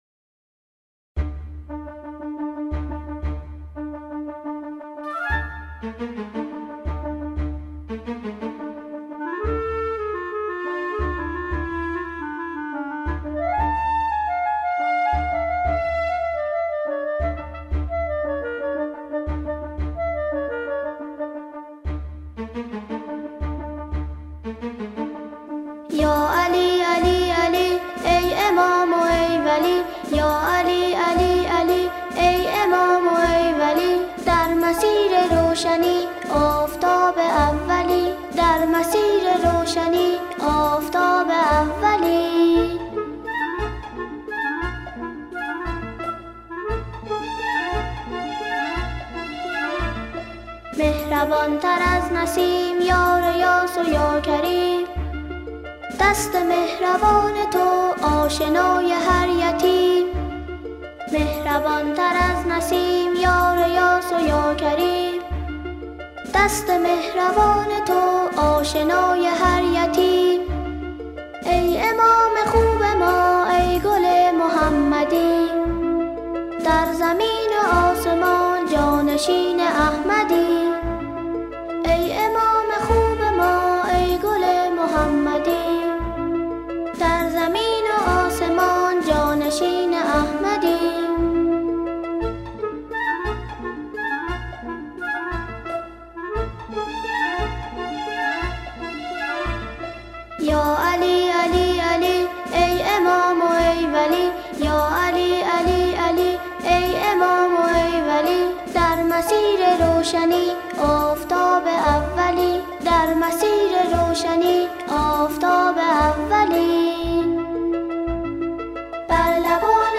همخوانی شعری درباره “عید غدیر خم” با صدای تکخوان کودک